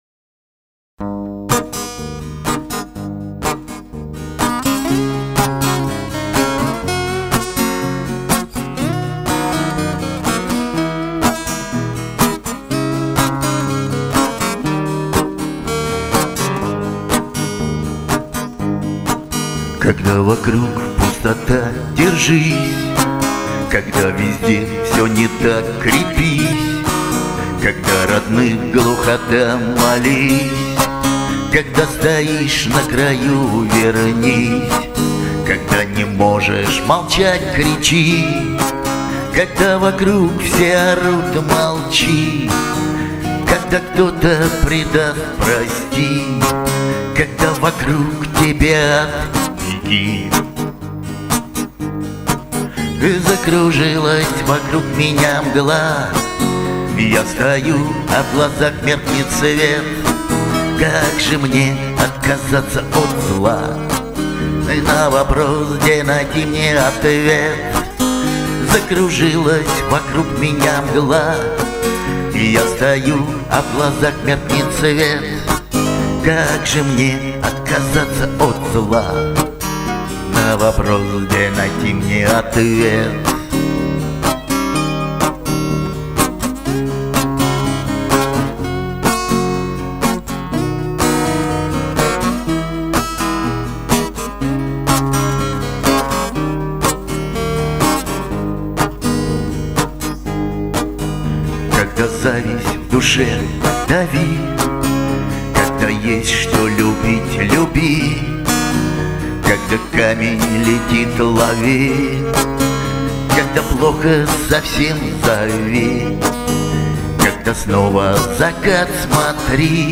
• Песня: Лирика